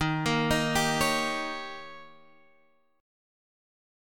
D#7 chord